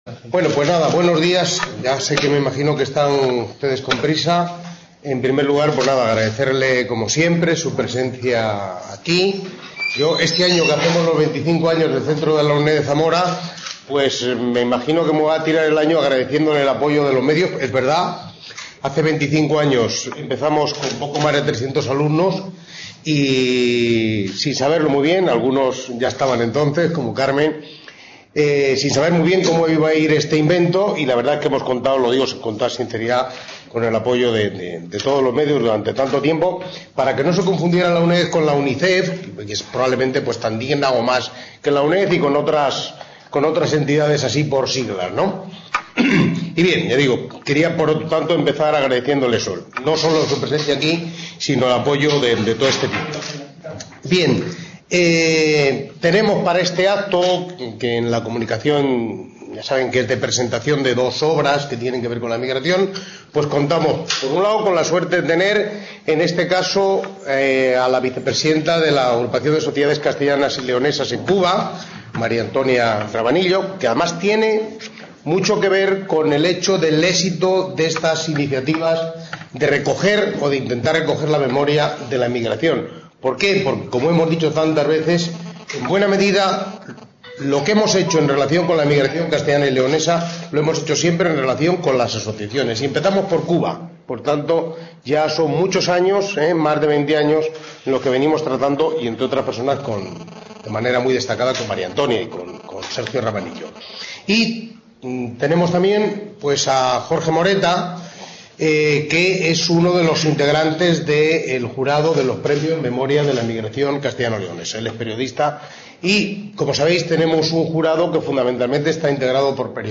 CA Zamora: Rueda de prensa presentación de dos nuevas…
DÍA: 5 de octubre de 2011 HORA: 11:00 horas LUGAR: Centro de la UNED de Zamora Edificio Colegio Universitario.